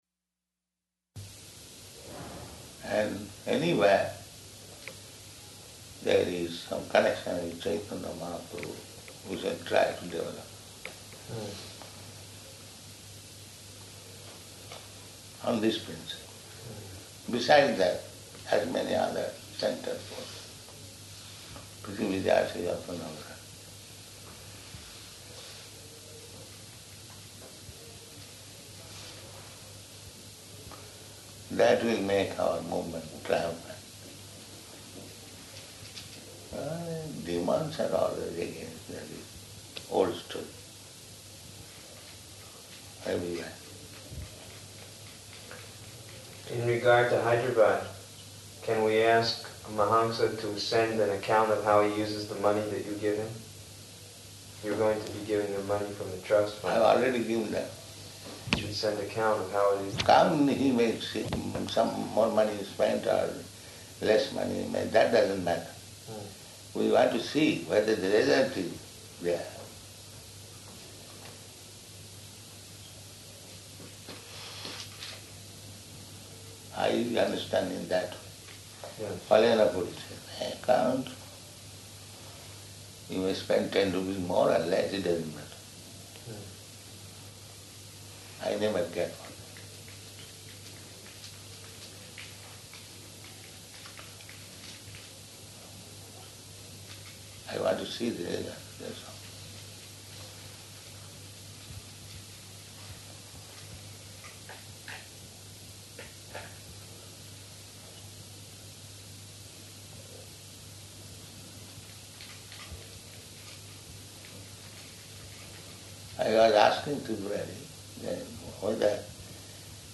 Room Conversation
Type: Conversation
Location: Māyāpur